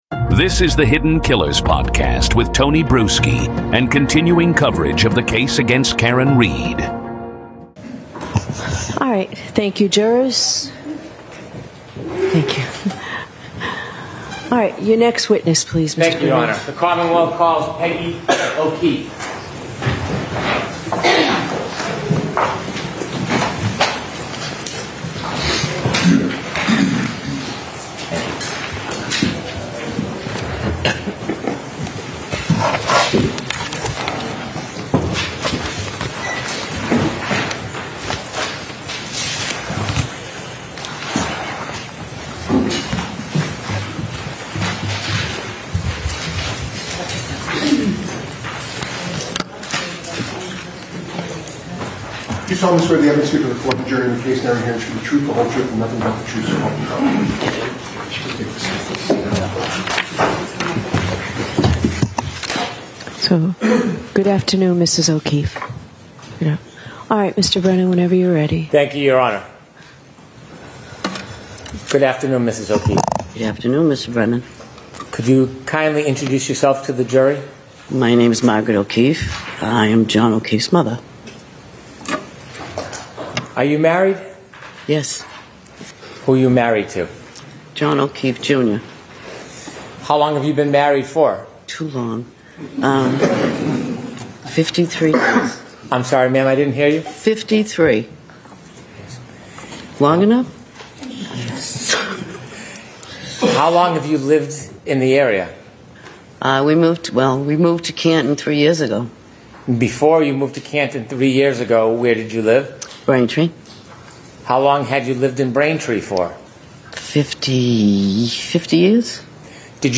In this gripping and emotional courtroom recording
Hear every word as it happened—this is one of the trial’s most emotionally charged episodes.